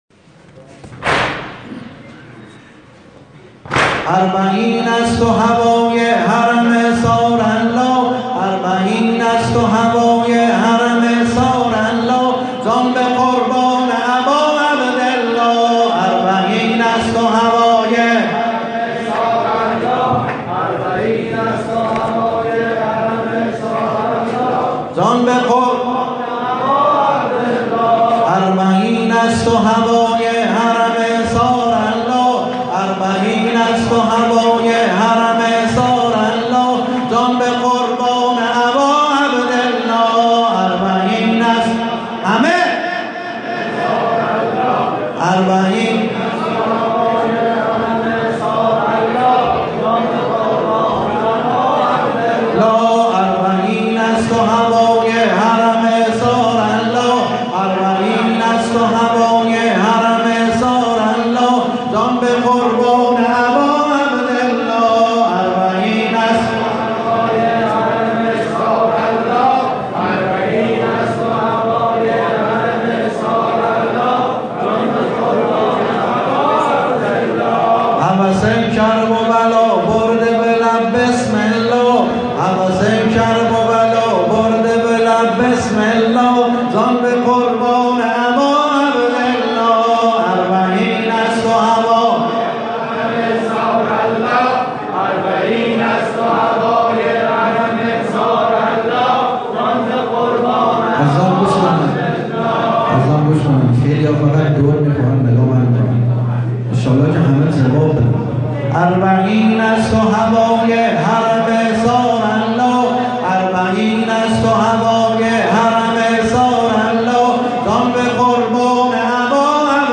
متن و سبک نوحه اربعین -( اربعین است و هوای حرم ثارالله )